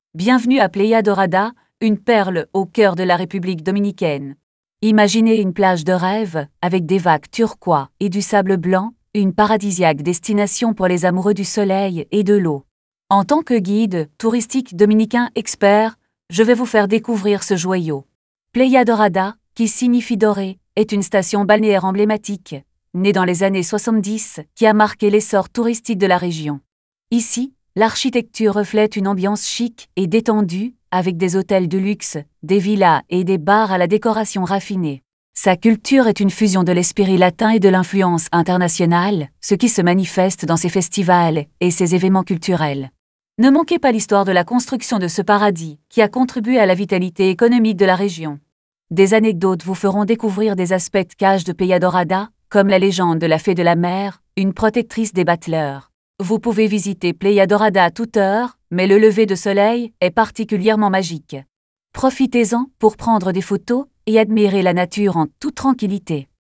karibeo_api / tts / cache / f6a47d08f41474dec77ff62c82408a4f.wav